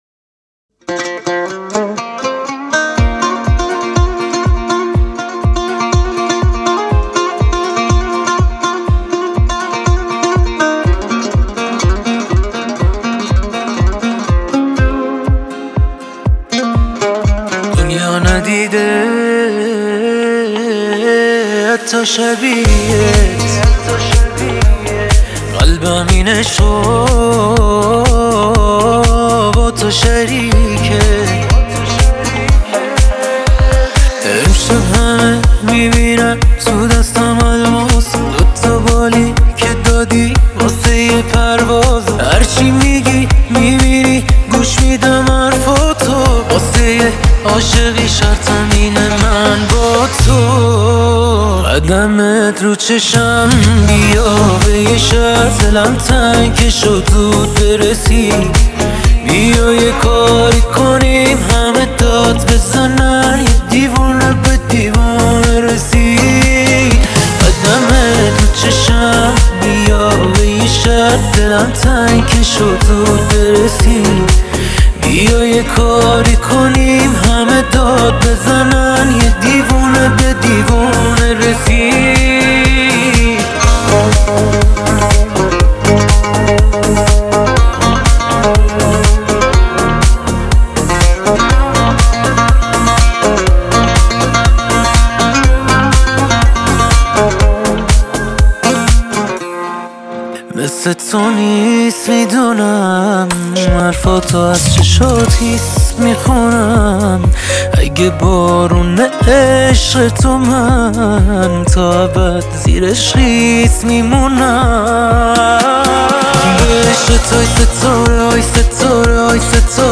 آهنگ شاد و عاشقانه